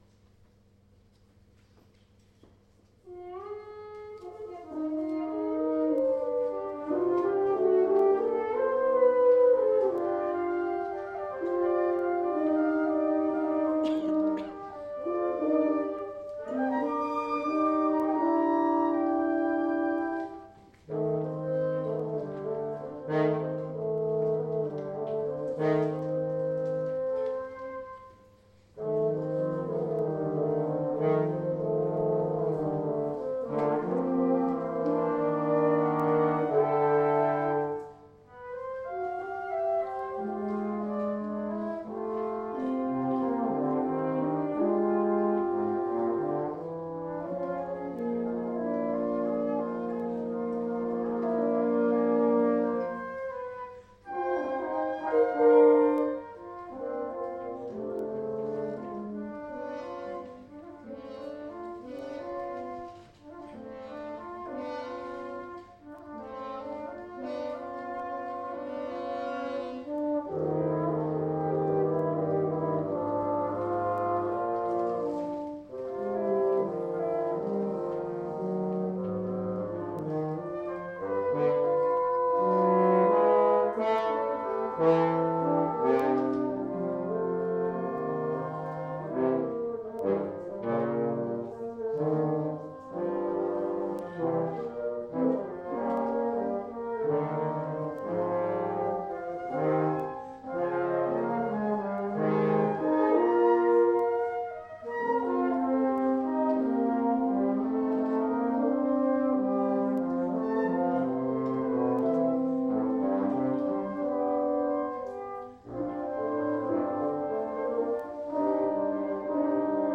File audio del Concerto, di compositori e interpreti del Conservatorio Bonporti, nell’ambito del Festival di poesia Anterem a Verona
Il concerto si è tenuto domenica 24 ottobre alla Biblioteca Civica di Verona, alle ore 11.00.
Una poesia singola per voce recitante, flauto contralto, fagotto, corno, trombone